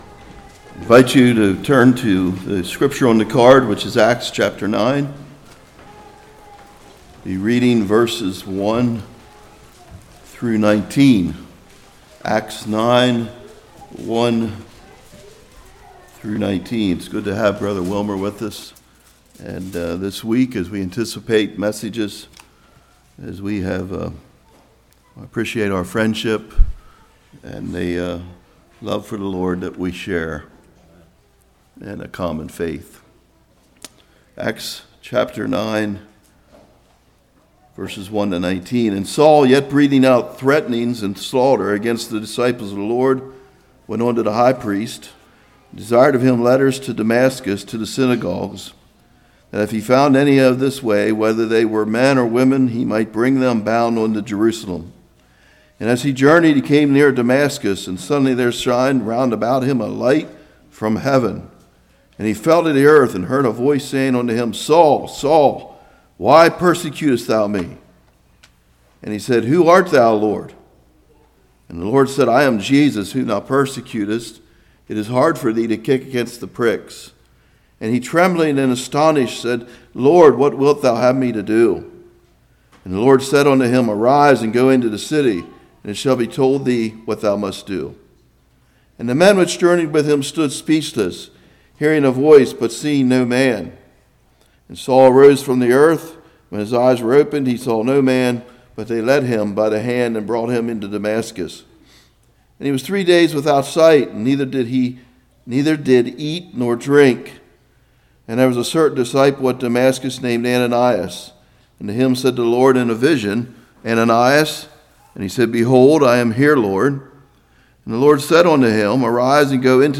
Acts 9:1-19 Service Type: Revival Response to God’s Calling.